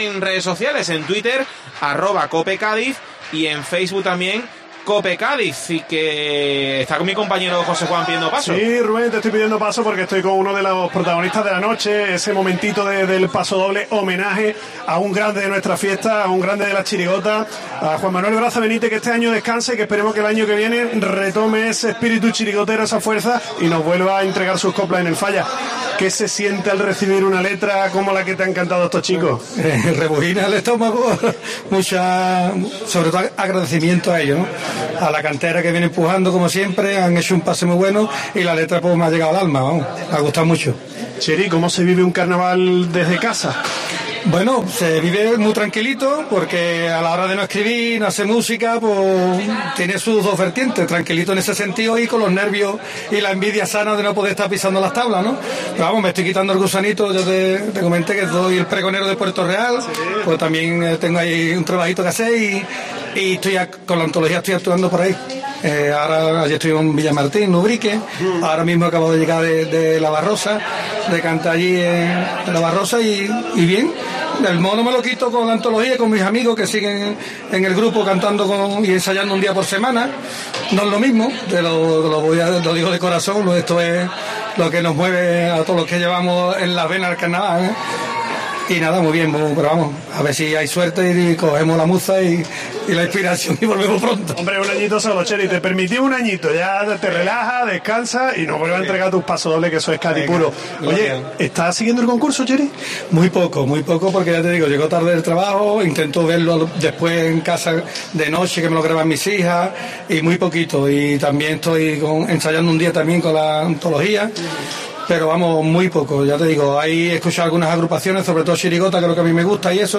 Entrevista en el Falla